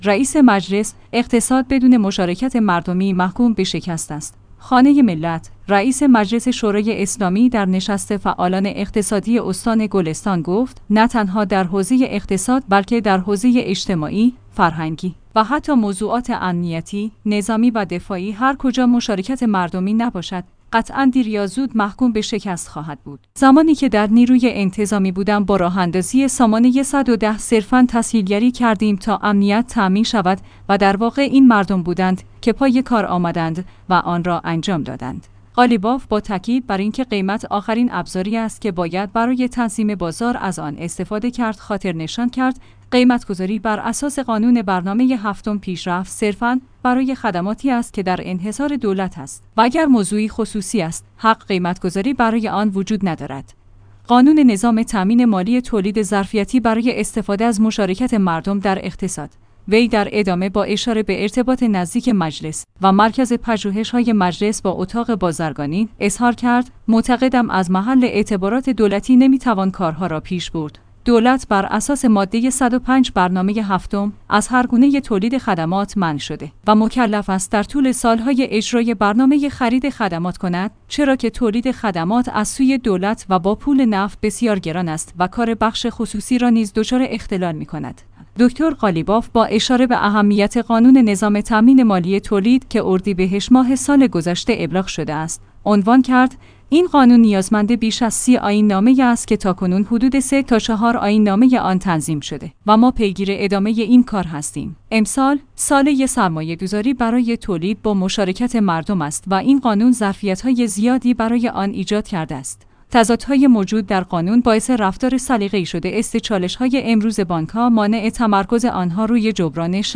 خانه ملت/ رئیس مجلس شورای اسلامی در نشست فعالان اقتصادی استان گلستان گفت: نه تنها در حوزه اقتصاد بلکه در حوزه اجتماعی، فرهنگی و حتی موضوعات امنیتی، نظامی و دفاعی هر کجا مشارکت مردمی نباشد، قطعاً دیر یا زود محکوم به شکست خواهد بود.